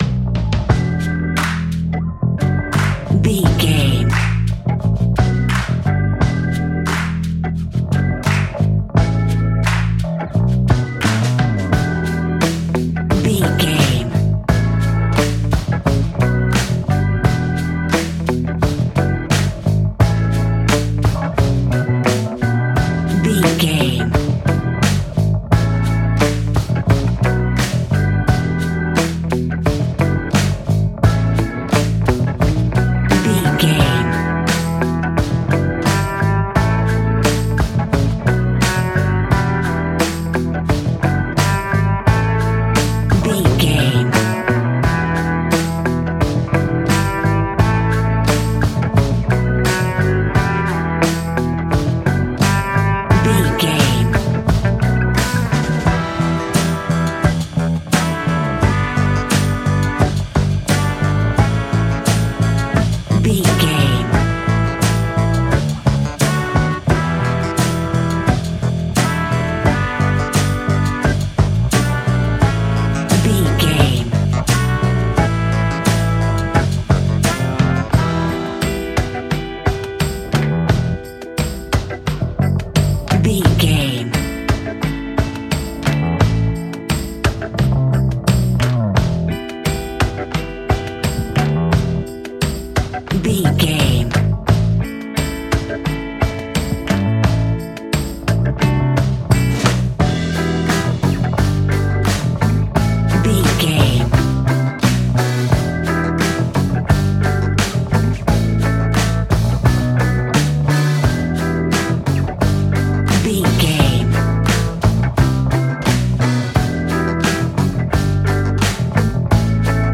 Aeolian/Minor
slow paced
Funk